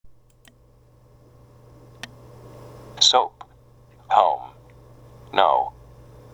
[オウ] soap, home, know